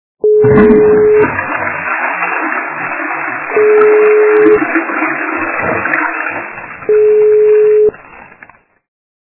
» Звуки » Смешные » Шустрая вода - в унитазе
При прослушивании Шустрая вода - в унитазе качество понижено и присутствуют гудки.
Звук Шустрая вода - в унитазе